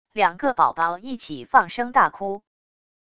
两个宝宝一起放声大哭wav音频